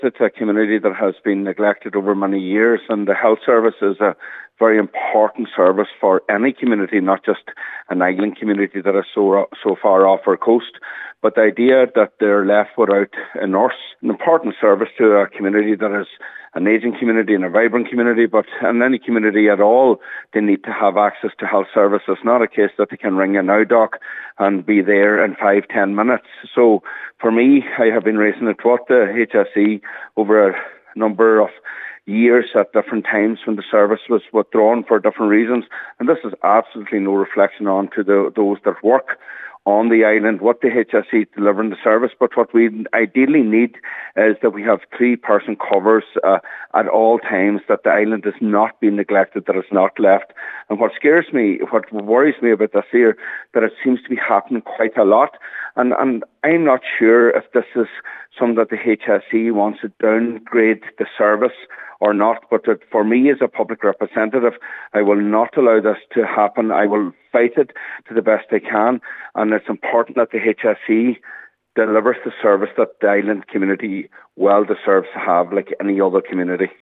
Cllr Mac Giolla Easbuig says it’s not fair people in Island communities have to suffer because they are so isolated………………..